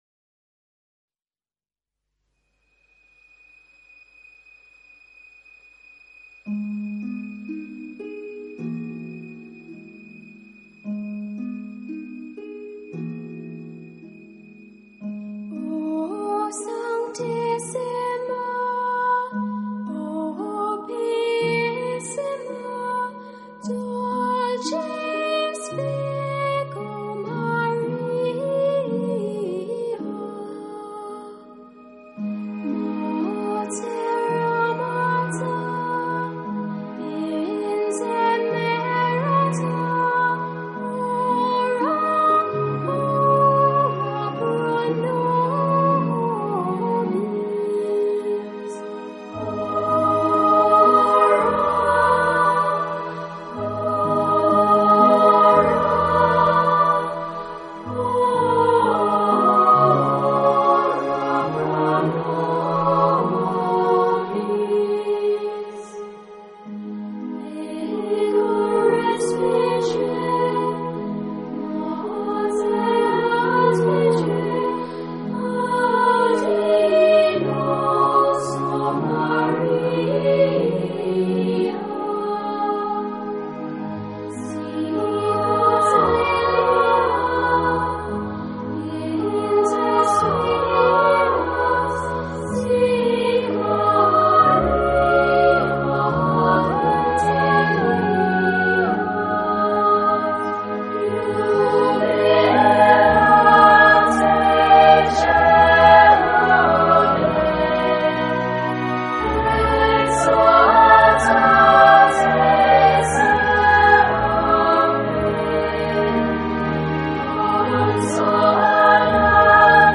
音樂風格︰New Age, Relax, Classics | 1CD |